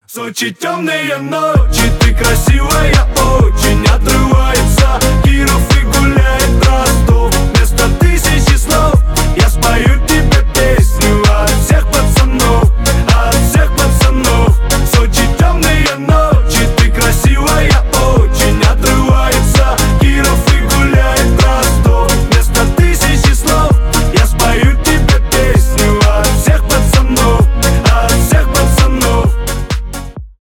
поп , веселые , танцевальные , позитивные